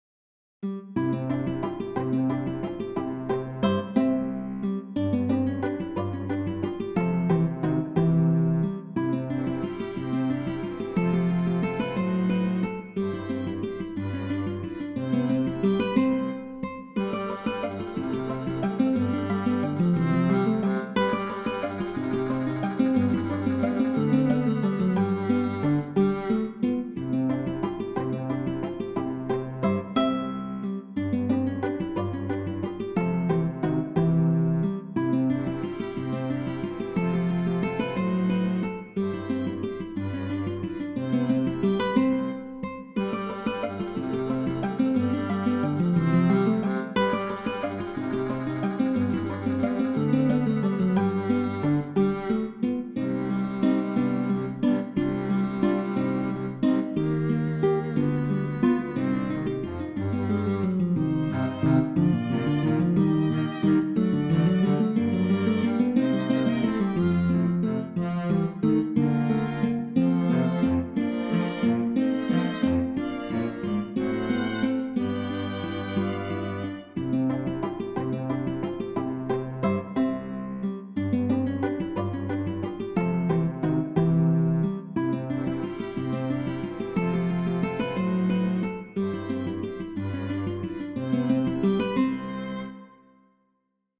with String Quartet
With String Quartet